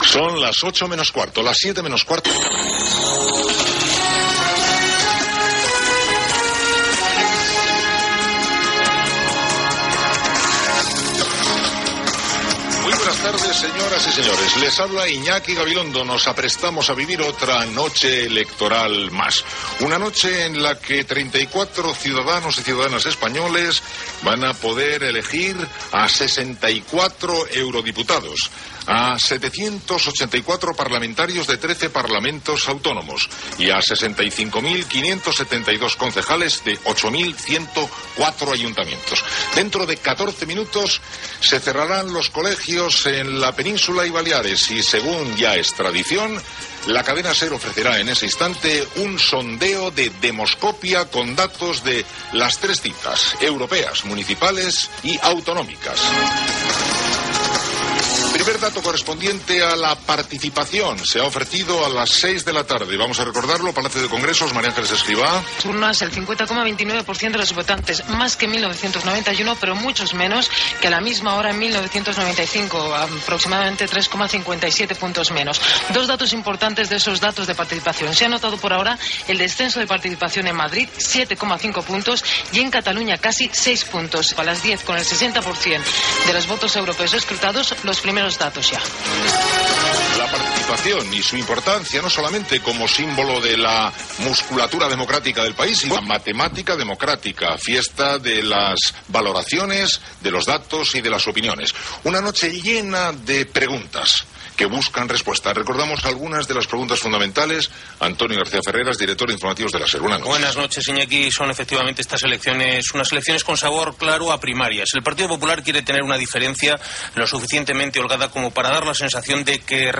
Preguntes que els resultats de les eleccions hauran de respondre. Connexions amb Andalusia, País Basc, Galícia, Madrid i Catalunya.
Sintonia de la Cadena SER.
Gènere radiofònic Informatiu